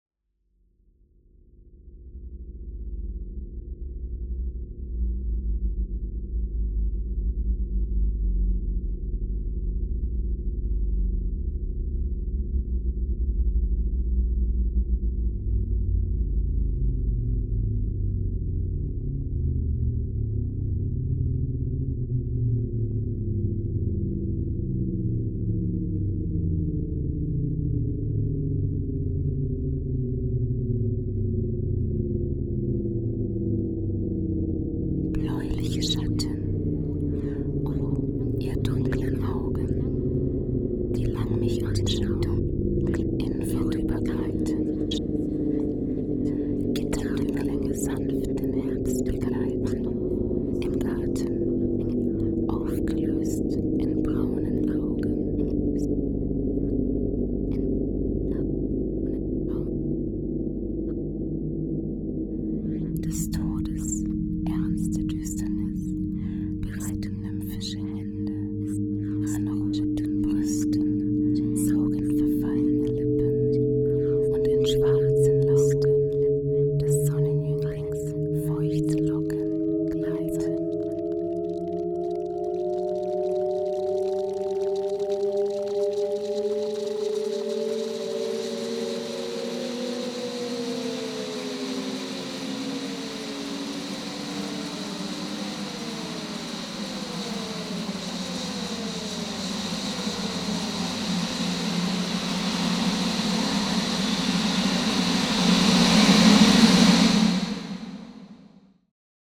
Meine Aufgabe bestand darin, die Gedichte auf verschiedenste Art und Weise einzusprechen.